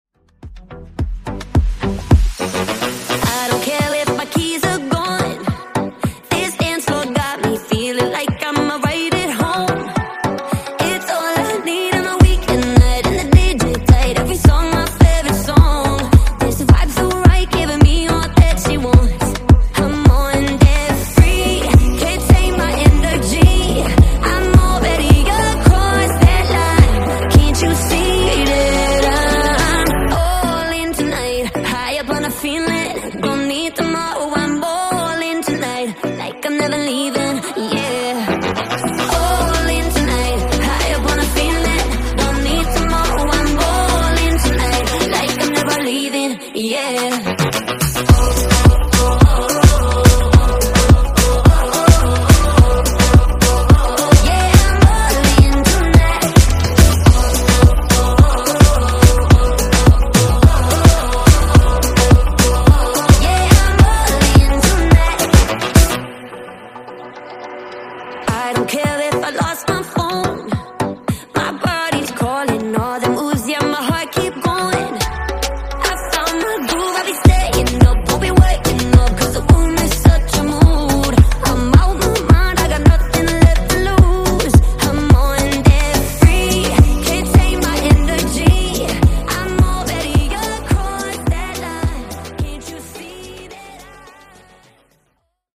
Genre: RE-DRUM
Clean BPM: 139 Time